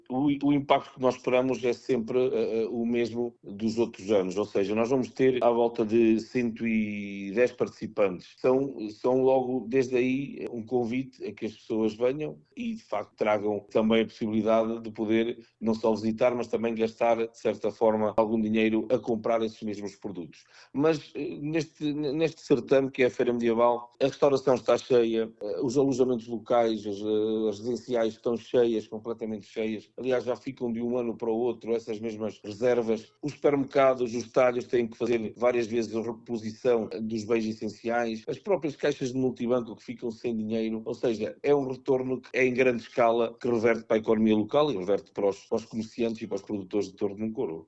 Segundo o presidente da Câmara, durante o evento, a restauração, os alojamentos e o comércio local atingem níveis elevados de ocupação, gerando um impacto económico significativo para produtores e comerciantes: